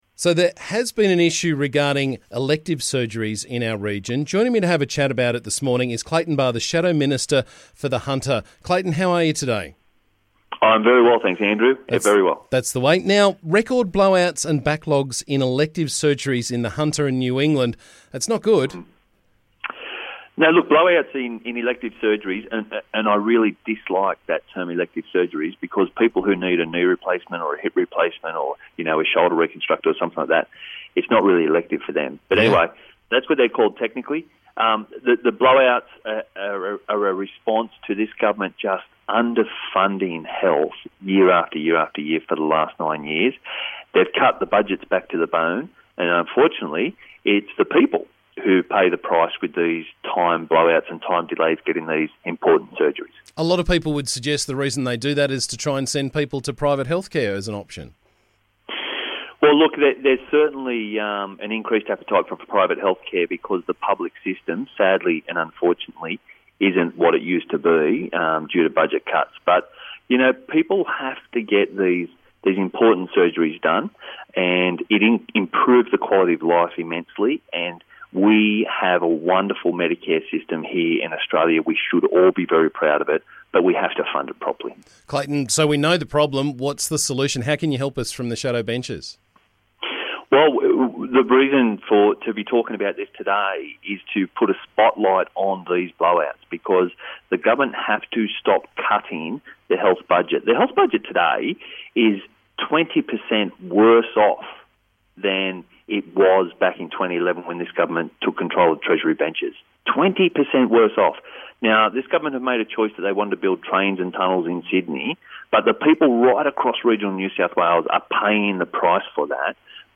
Shadow Minister for The Hunter Clayton Barr joined me this morning to talk about the backlogs with elective surgeries in the Hunter.